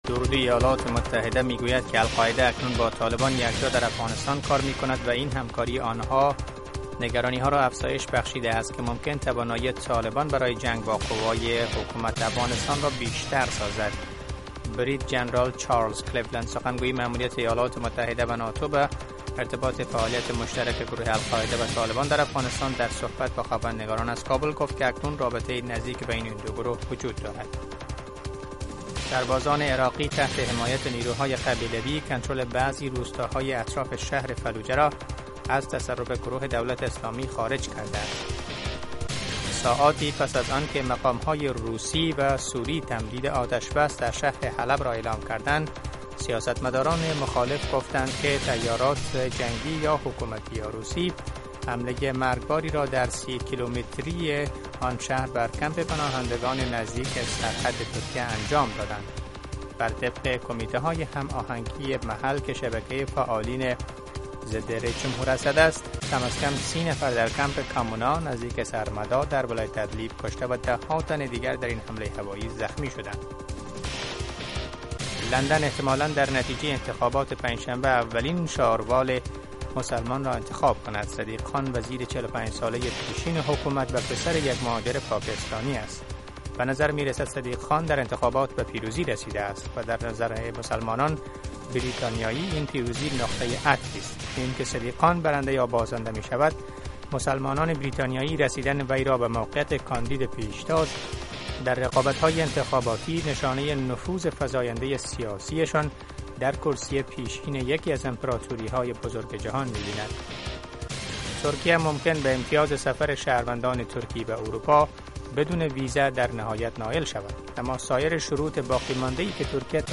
تازه ترین خبرهای افغانستان و جهان در سه دقیقه